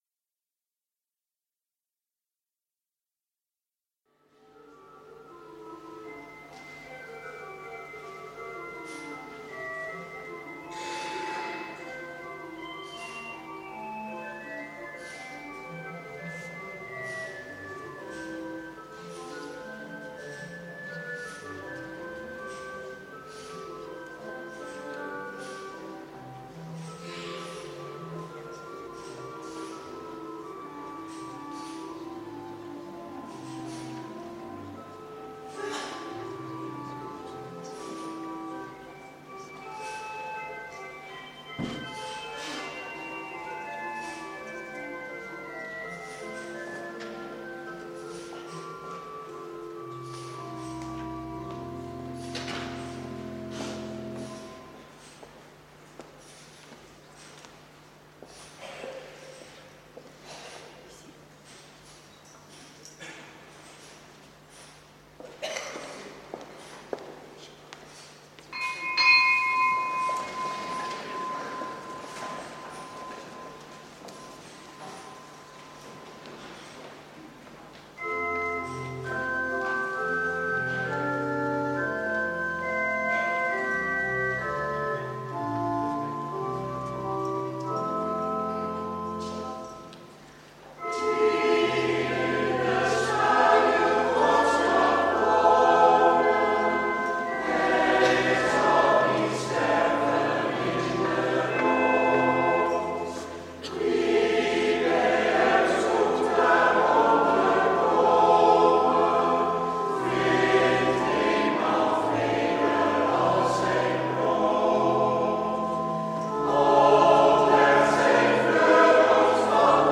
Eucharistieviering beluisteren vanuit de H. Willibrord te Oegstgeest (MP3)